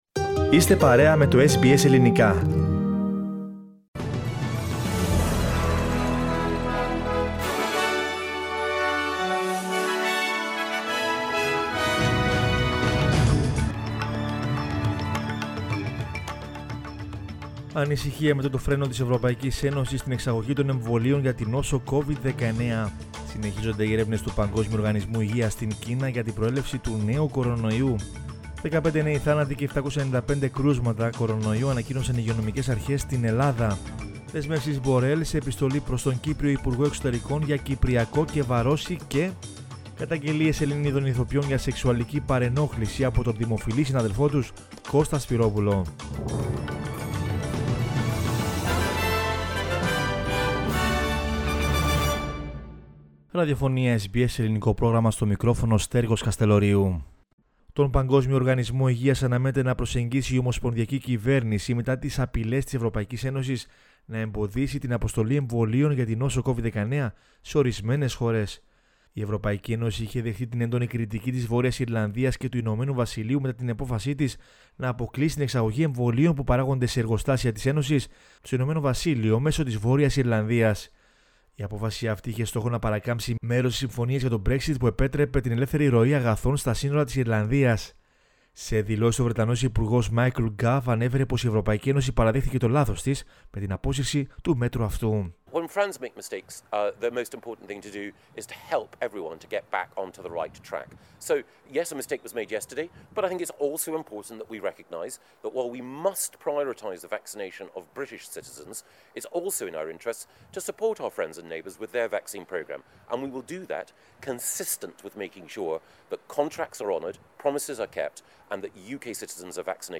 News in Greek from Australia, Greece, Cyprus and the world is the news bulletin of Sunday 31 January 2021.